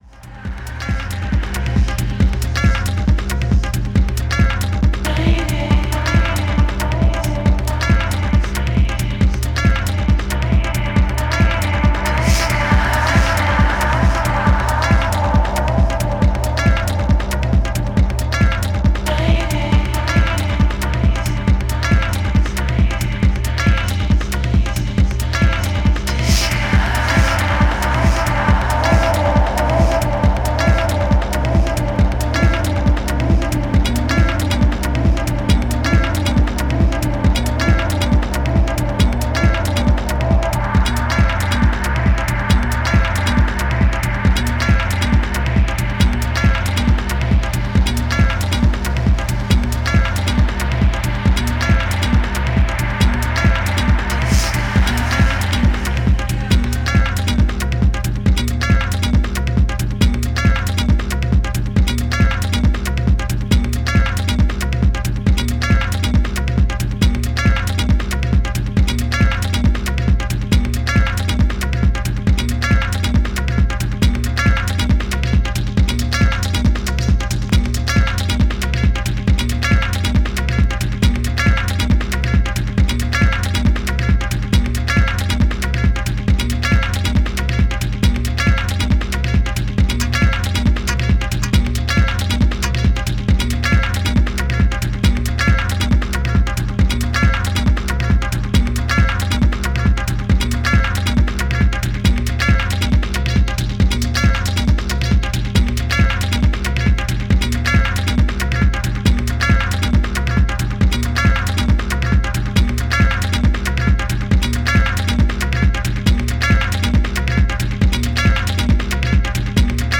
STYLE Techno